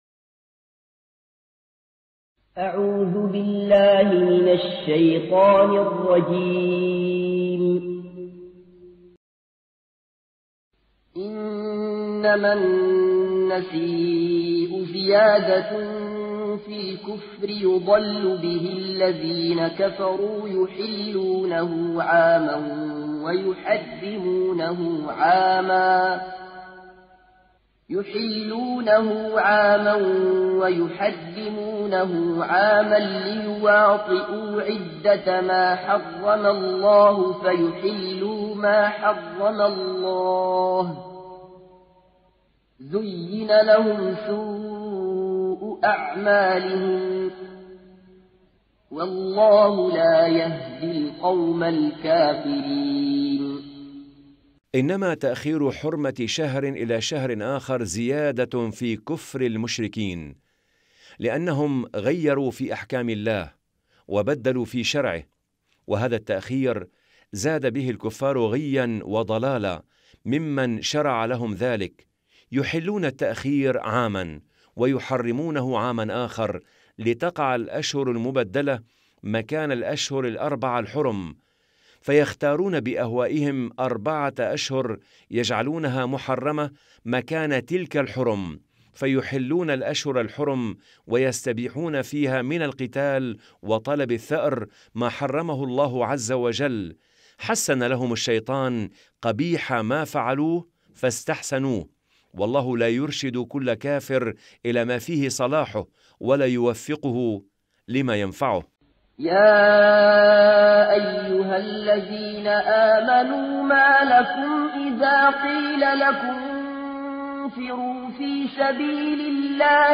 مجلدات التفسير تلاوة تعليمية للقرآن الكريم مع التفسير الميسر